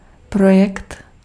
Ääntäminen
US : IPA : /ˈpɹɑd͡ʒɛkt/